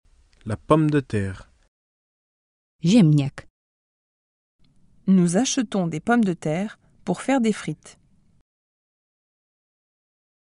- słuchając nagrań native speakerów, nauczysz się prawidłowej wymowy
Możesz posłuchać nie tylko słówka wraz z polskimi tłumaczeniami, ale też przykładowe zdania w wykonaniu profesjonalnych lektorów.
Przykładowe nagranie z tłumaczeniem